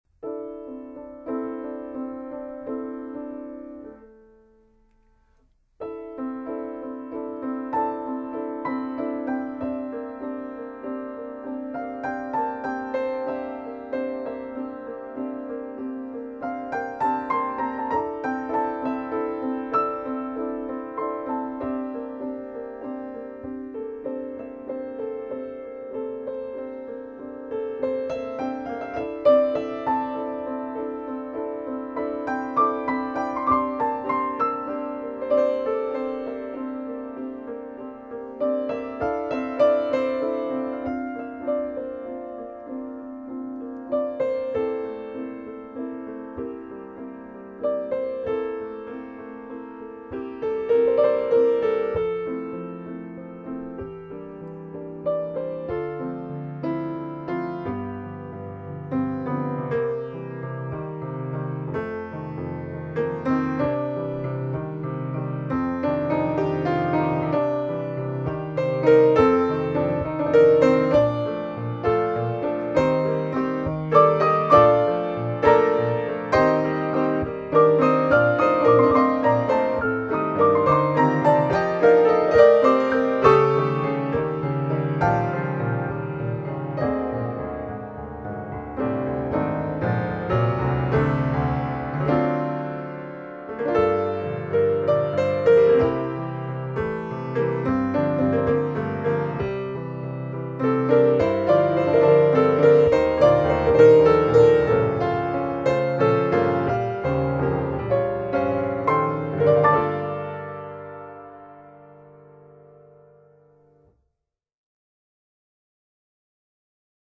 complemento del titolo: Suite per pianoforte a 4 mani.
mezzo di esecuzione: pianoforte a 4 mani